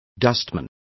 Complete with pronunciation of the translation of dustman.